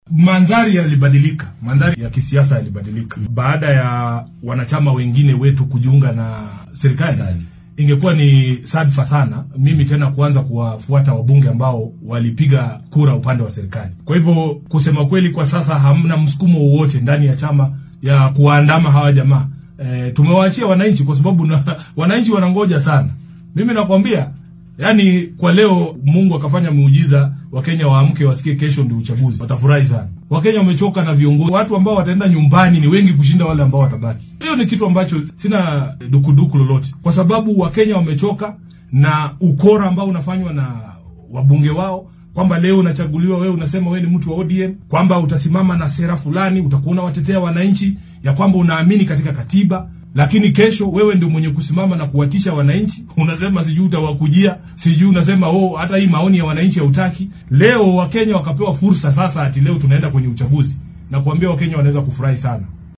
Hasa ahaate , Edwin Sifuna oo saaka wareysi gaar ah siinayay idaacadda maxalliga ee Radio Citizen ayaa carrabka ku adkeeyay in go’aankii hore laga tanaasulay.